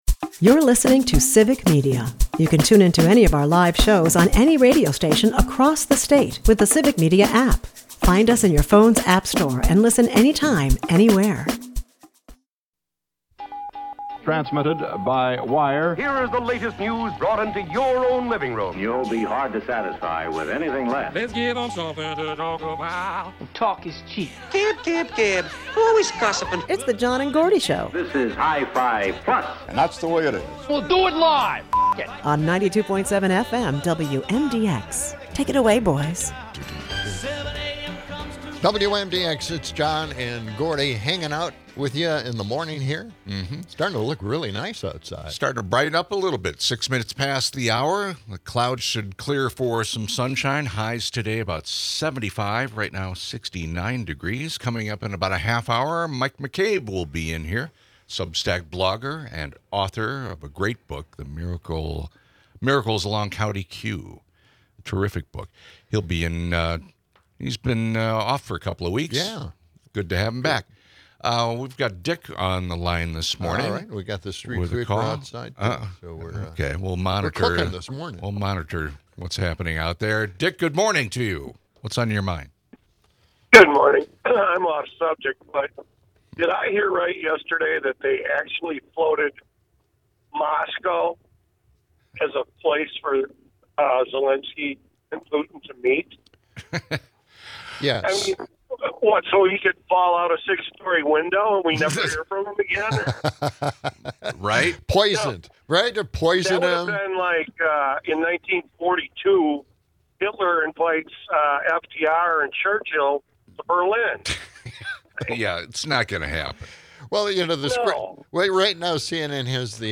A satirical rant from Charlie Angus delivers a punch to U.S. values, while weather updates and party invites lighten the tone. The episode ends with a call for imagination and caution against AI overreach.